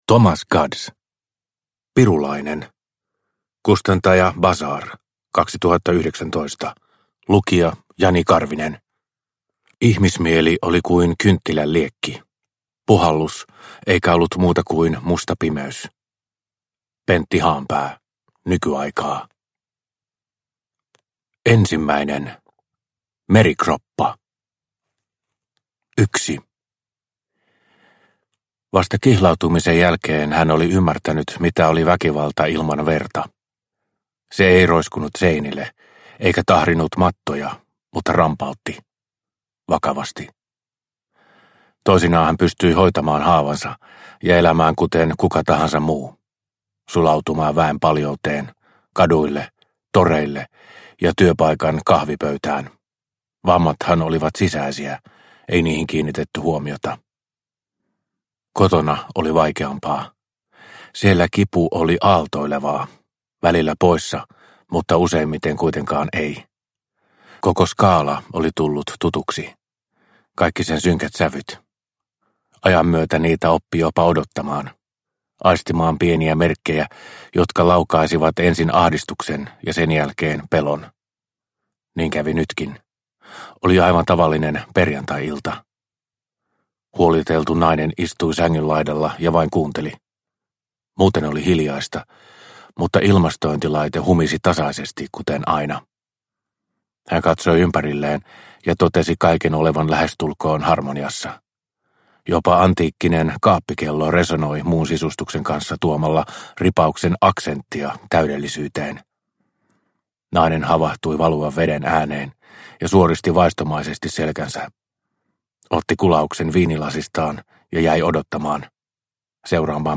Pirulainen – Ljudbok – Laddas ner